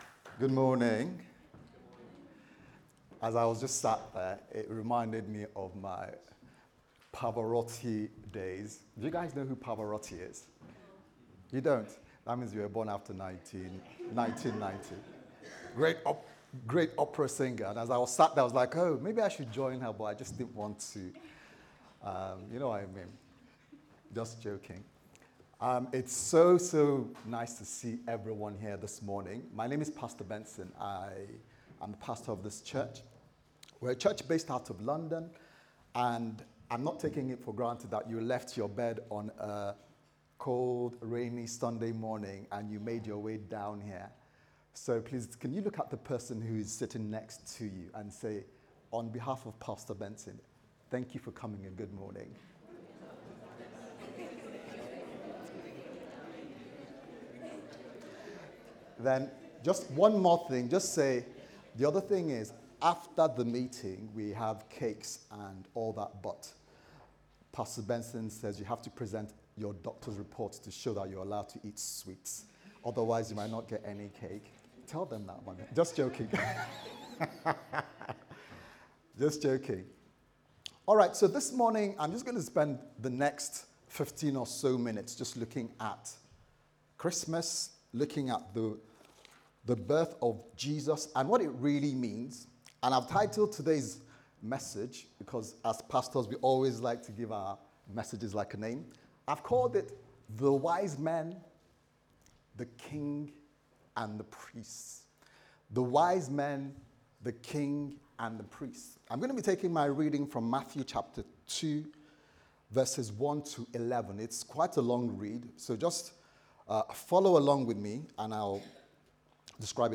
Sunday Service Sermon